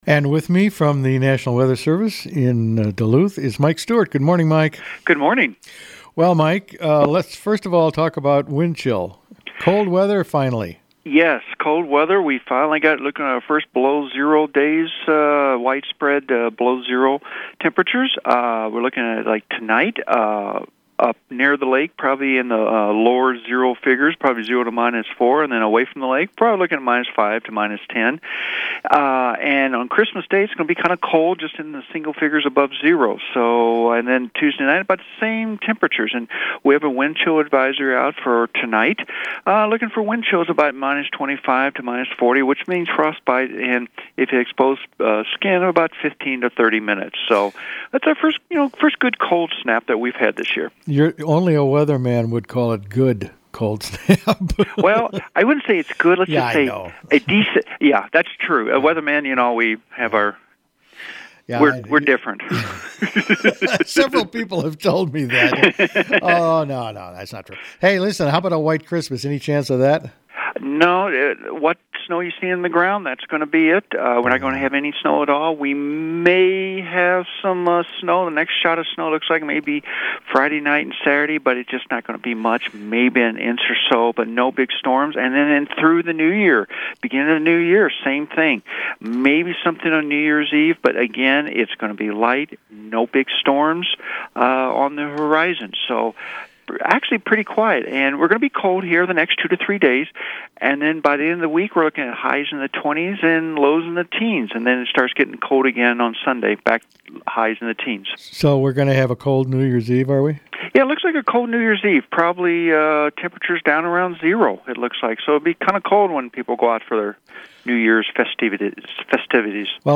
spoke with meteorologist